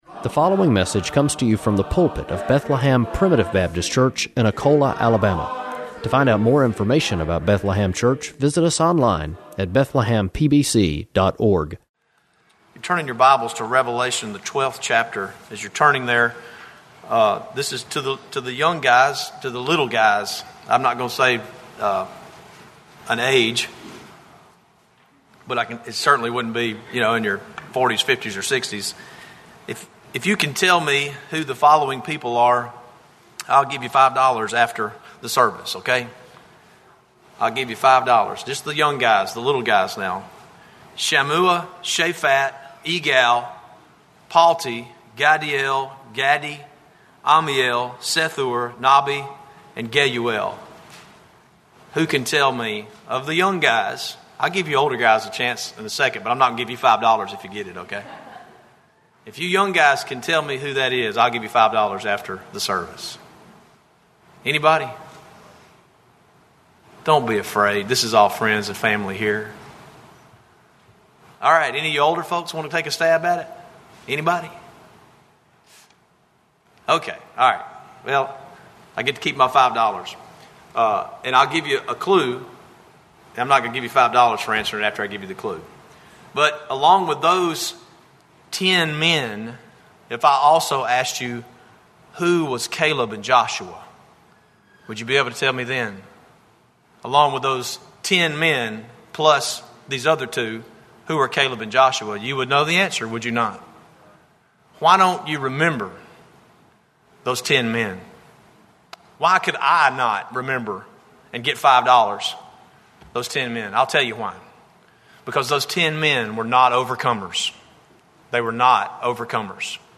Preached April 23